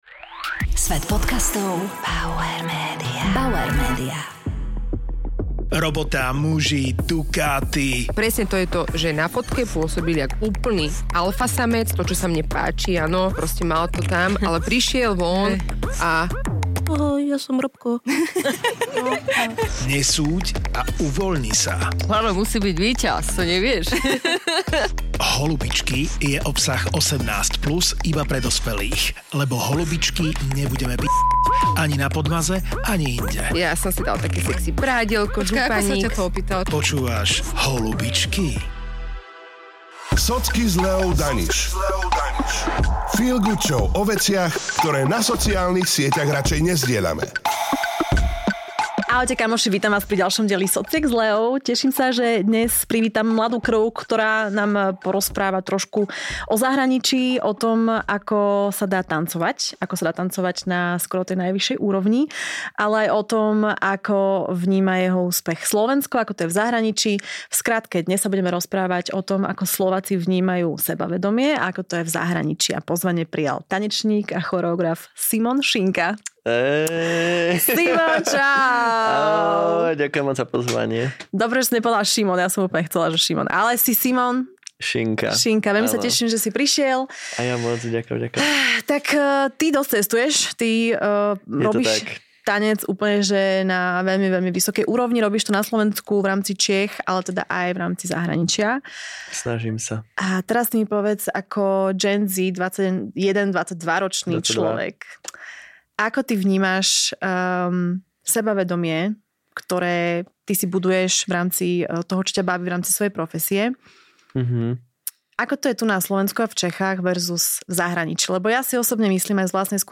Vypočuj si silný a úprimný rozhovor o perfekcionizme, sociálnych bublinách a o tom, ako si chrániť vlastnú hodnotu v krajine, kde je „vyčnievanie“ stále problém.